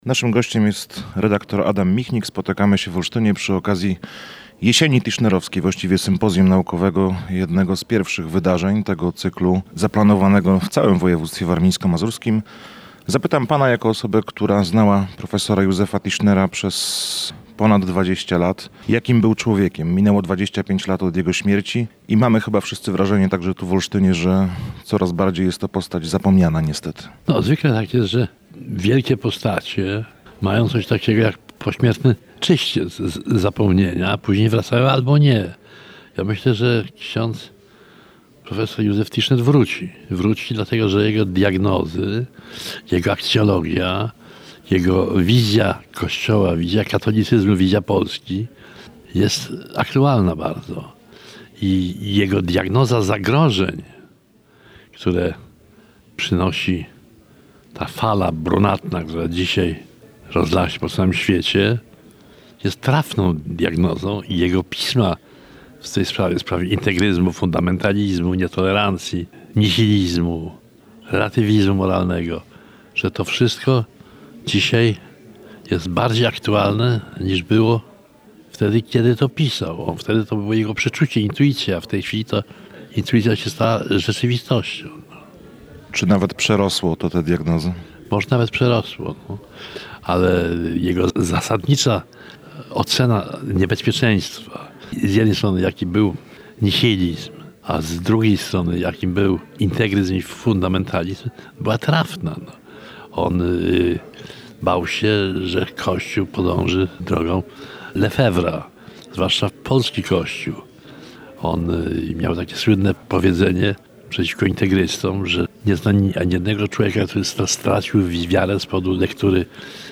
– mówił w Kortowie Adam Michnik.